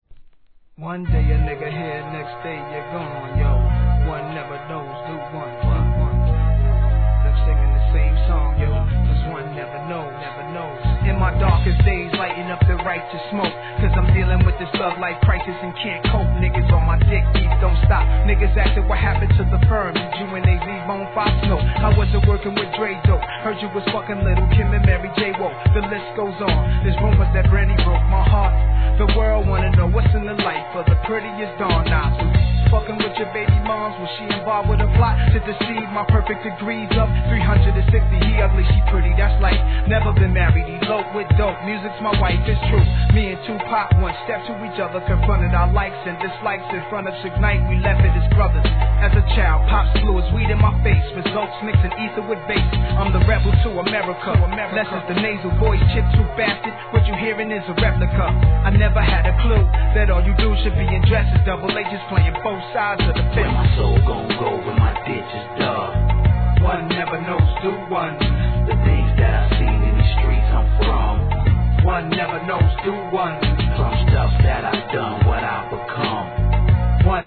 HIP HOP/R&B
ハーコー・アングラ!!